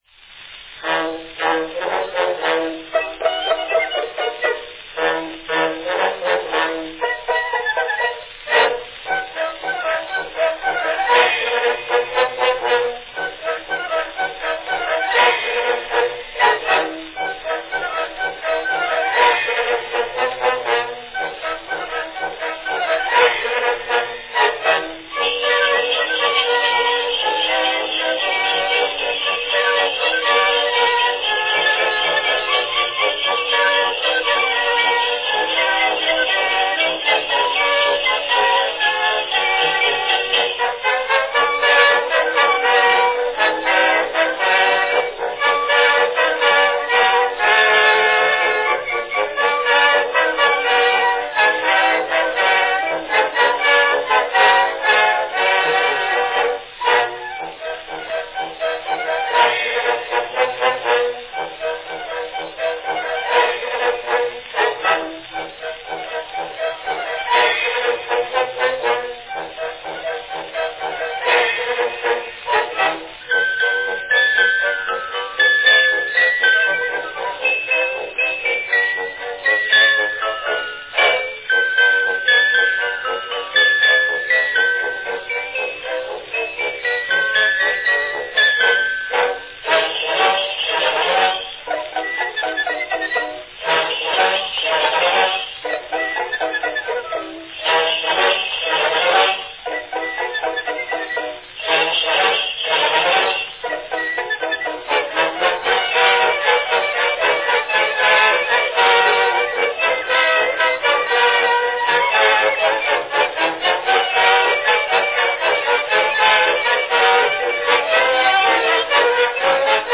A snappy recording
Category Characteristic march
Announcement None